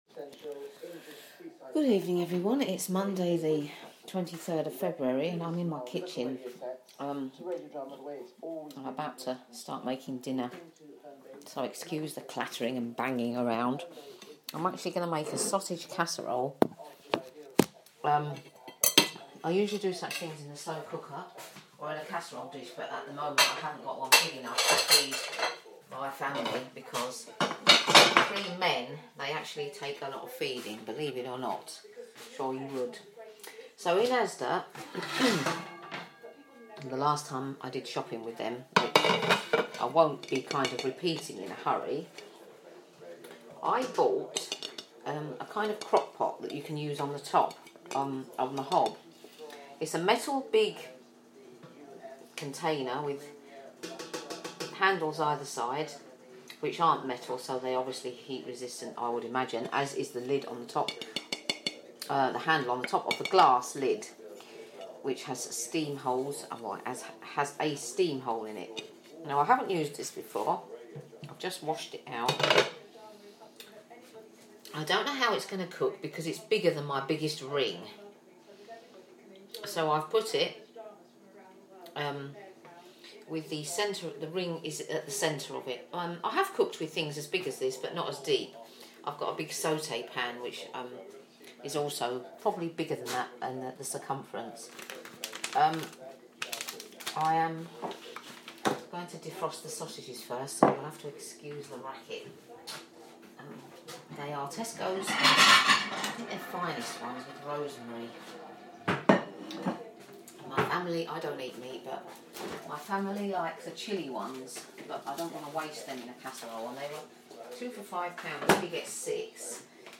join me cooking dinner in the kitchen while I rant about the days news